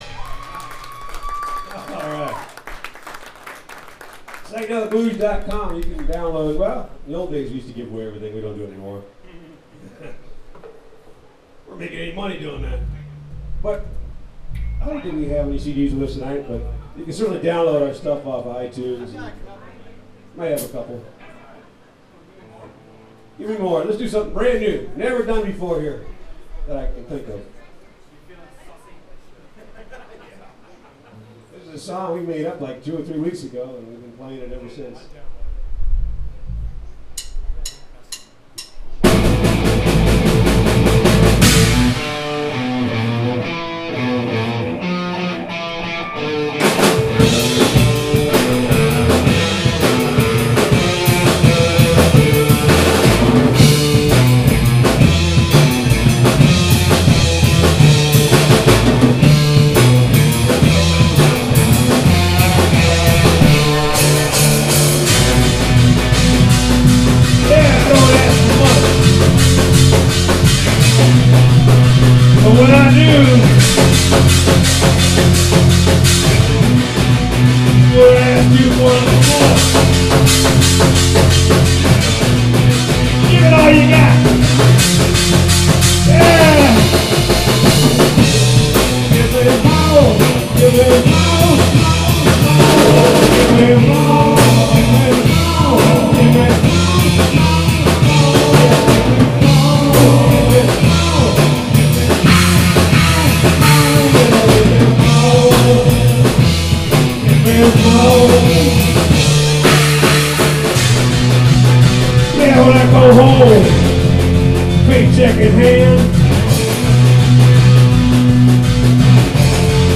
We try to record every show.